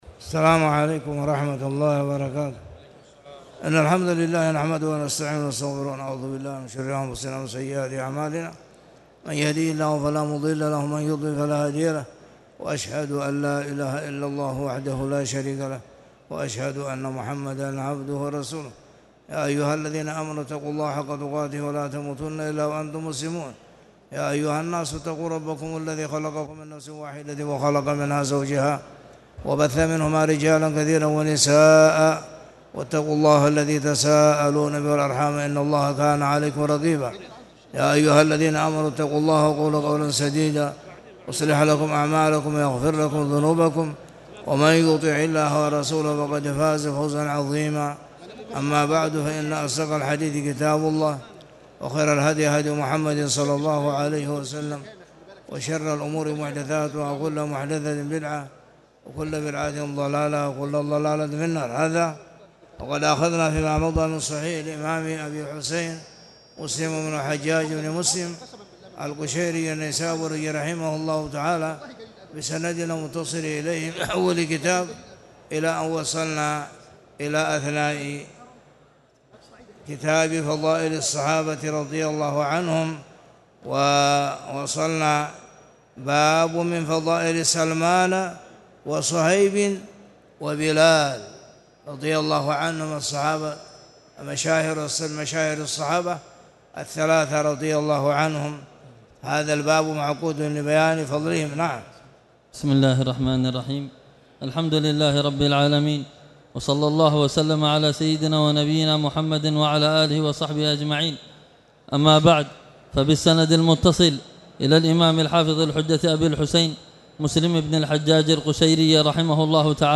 تاريخ النشر ٢٠ ربيع الأول ١٤٣٨ هـ المكان: المسجد الحرام الشيخ